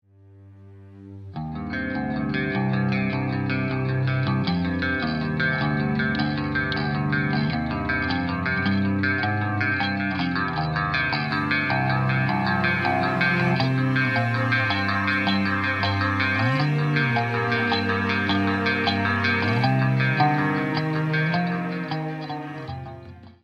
гитара
громкие
без слов
красивая мелодия
инструментальные
Крутой гитарный проигрыш из песни известной рок-группы.